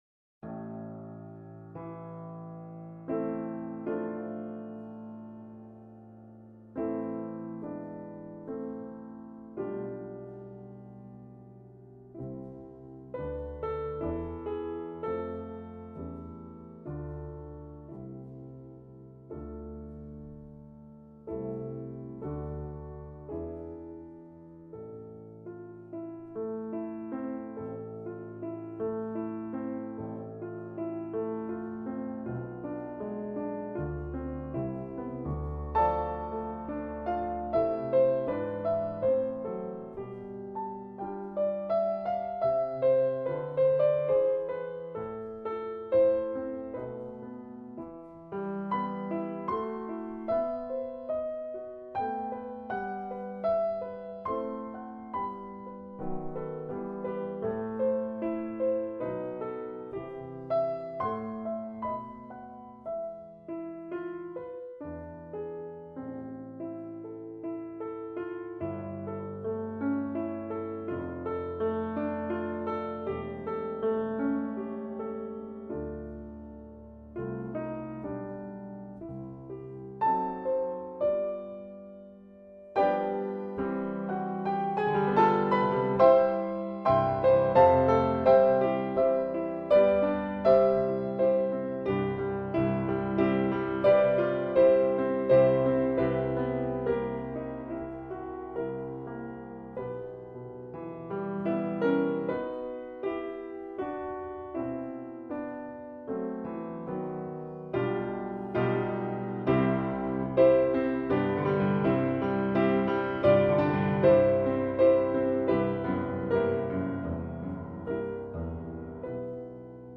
Romance no 1 Backing Track